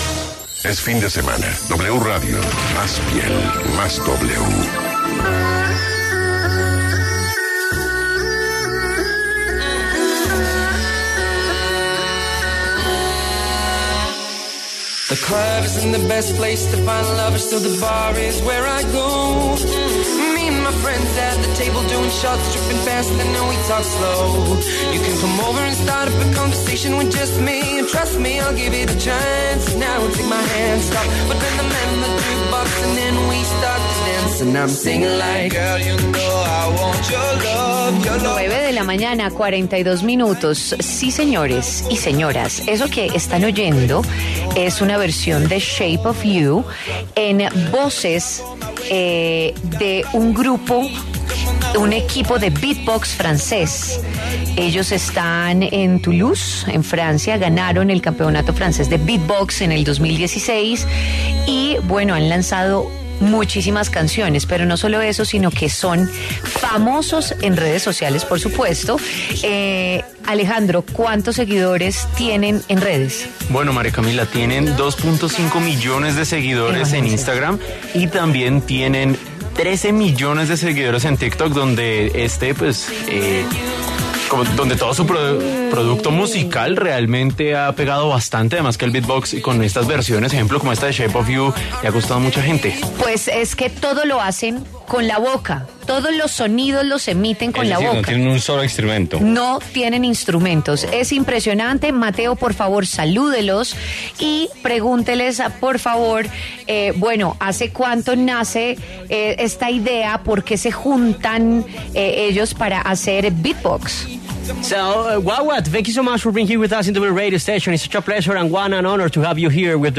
Instrumentos hechos con la boca, así es el ‘beatbox’ de ‘Berywam’